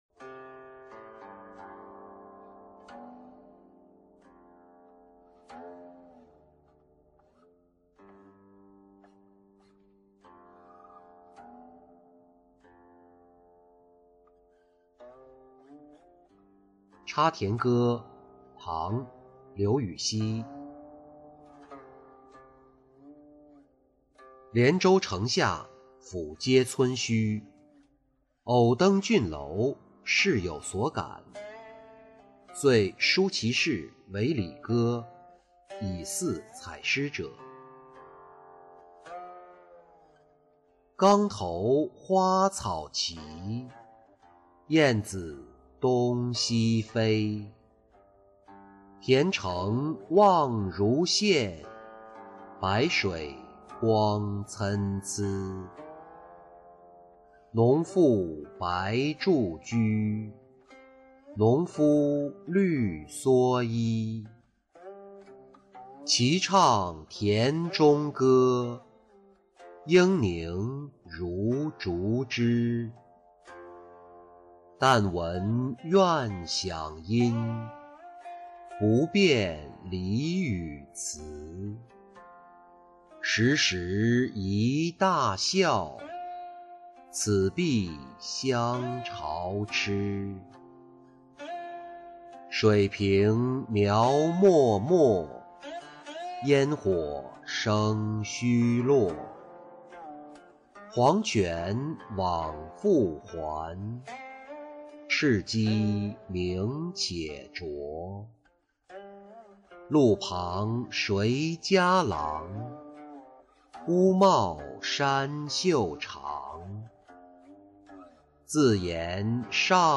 插田歌-音频朗读